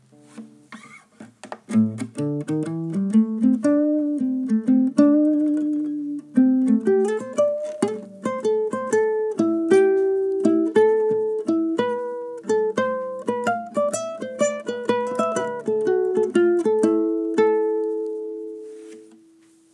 Blues_1_viol.wav